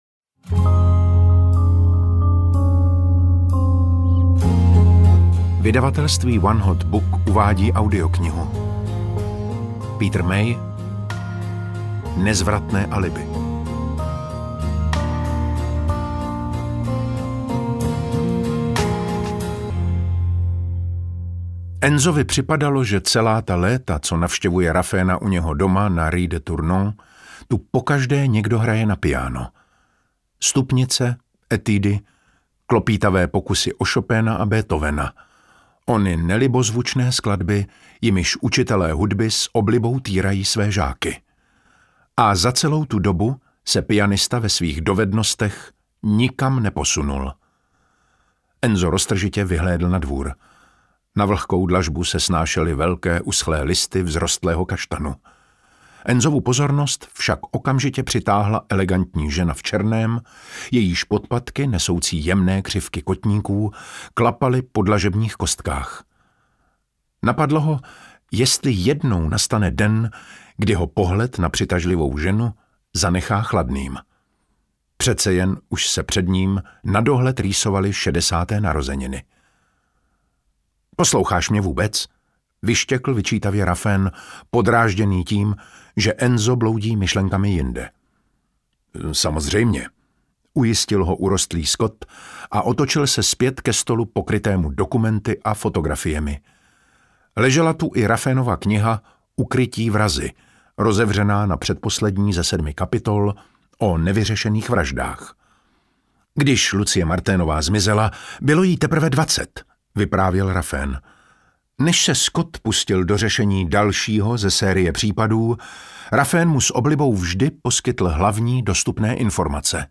Nezvratné alibi audiokniha
Ukázka z knihy